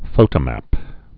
(fōtə-măp)